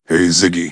synthetic-wakewords
ovos-tts-plugin-deepponies_Kratos_en.wav